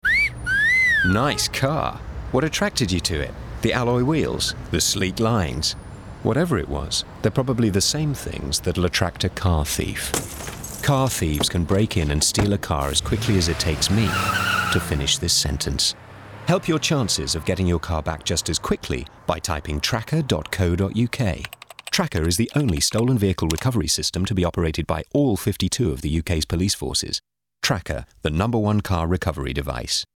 Straight
Commercial, Conversational, Informative